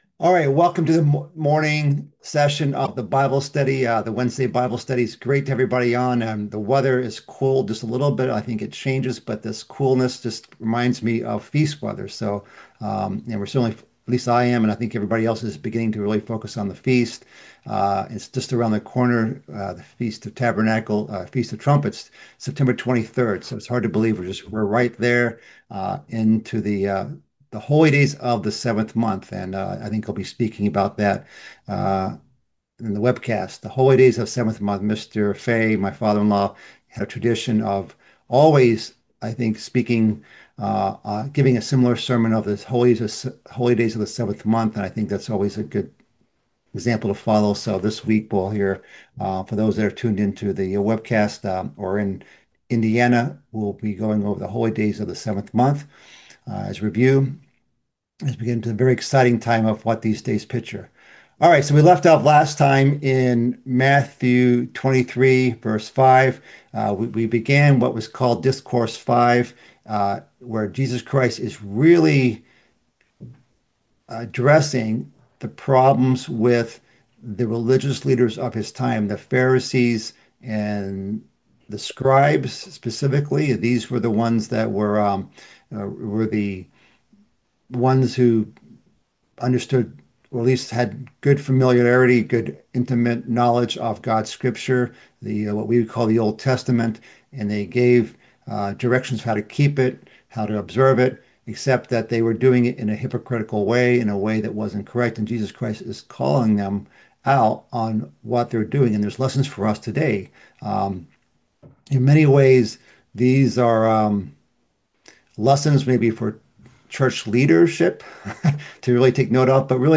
This is the second part of a mid-week Bible study series covering Christ's fifth discourse in the book of Matthew. Continuing in the section about woes to the scribes and Pharisees.